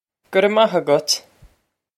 Pronunciation for how to say
Guh rev mah a-gut.
This is an approximate phonetic pronunciation of the phrase.
This comes straight from our Bitesize Irish online course of Bitesize lessons.